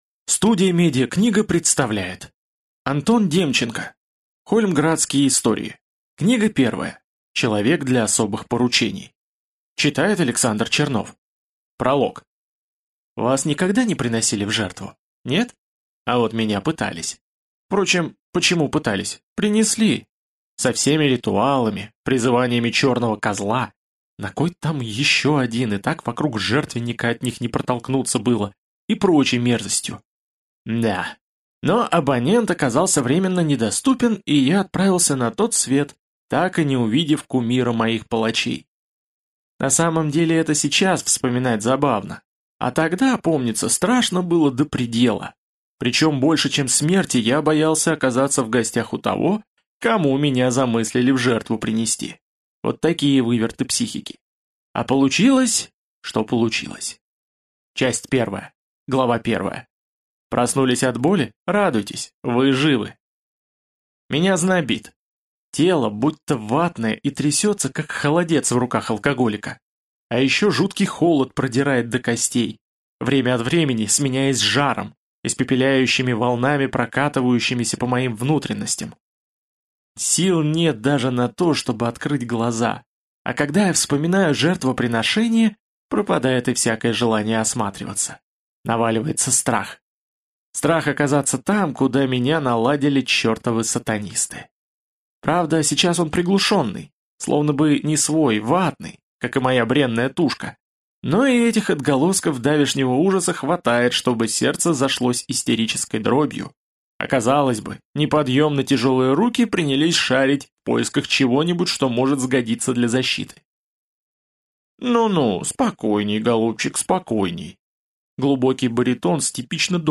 Аудиокнига Человек для особых поручений | Библиотека аудиокниг